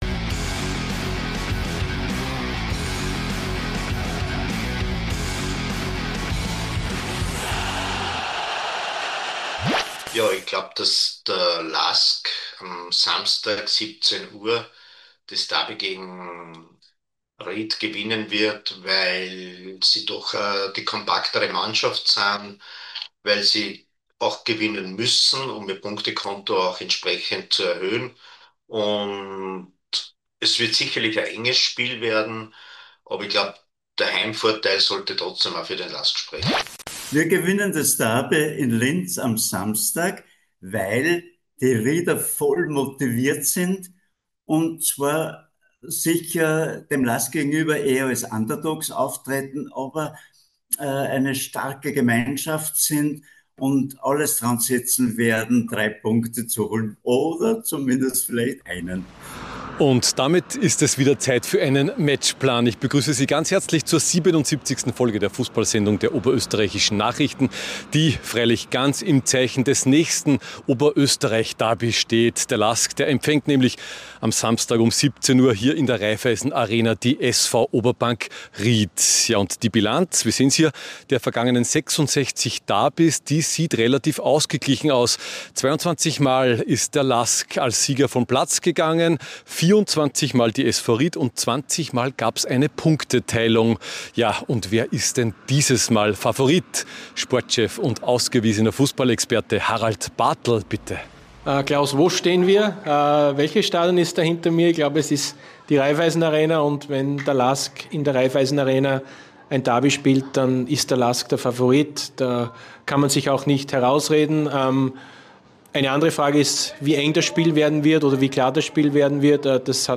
Am Samstag ist es Zeit für das nächste Oberösterreich-Derby: Der LASK empfängt die SV Oberbank Ried. Fans, Experten und Trainer analysieren die noch heute große Bedeutung dieses Duells.